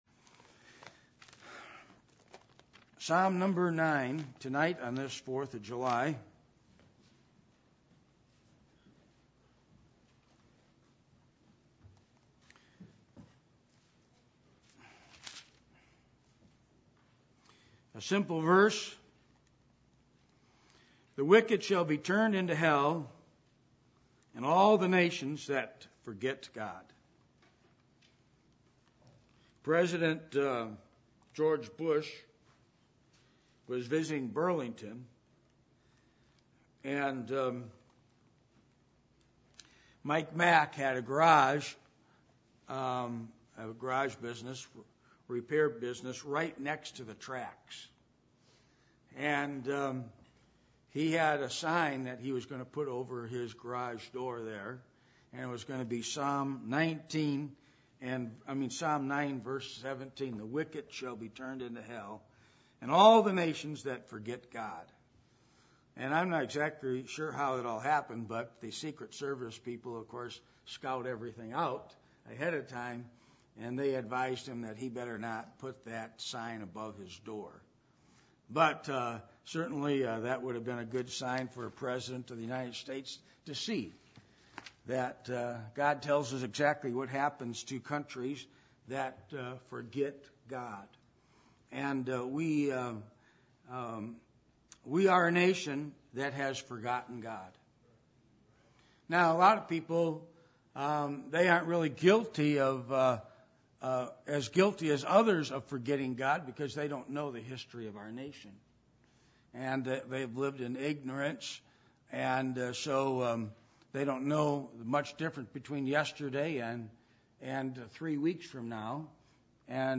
Psalm 9:17 Service Type: Midweek Meeting %todo_render% « Being Approved As A Minister Of God Biblical Principles Of Finances